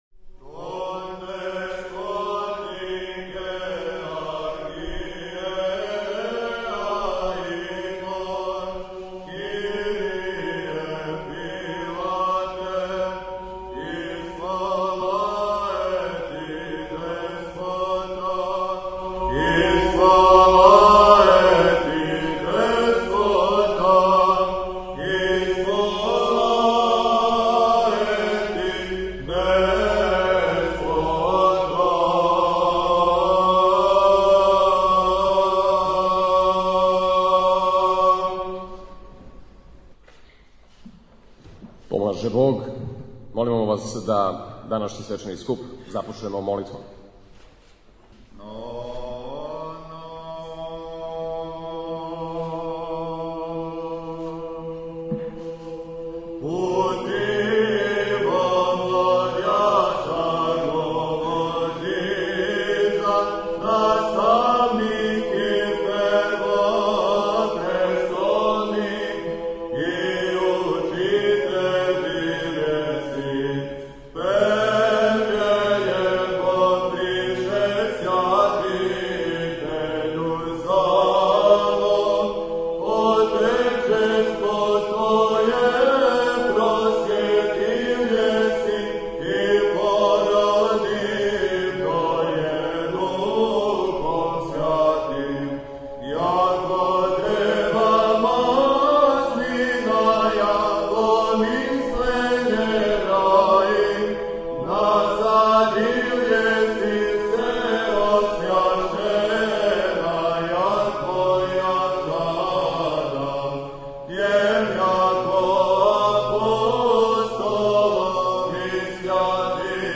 Београд - У свечаној сали Скупштине Града Београда, 24.12.2015. одржана је оснивачка скупштина Хиландарског лекарског друштва.